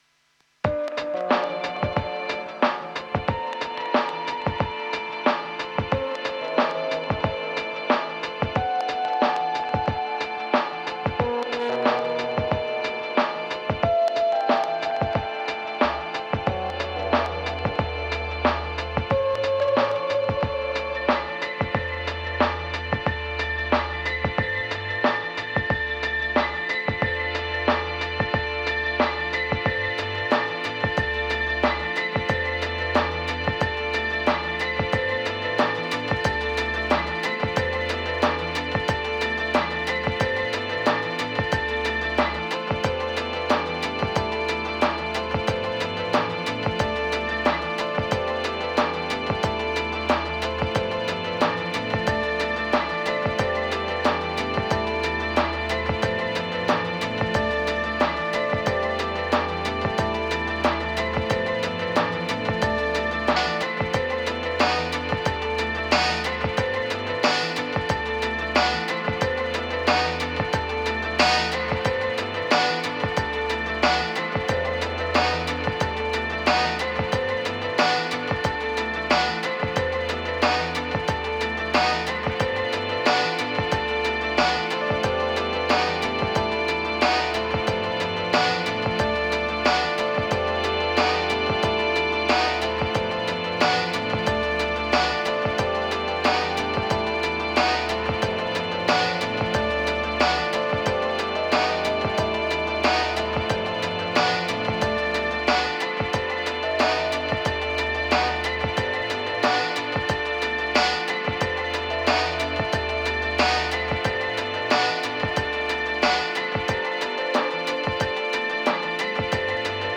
Triphop, Dub, Vibes, Groove, Bass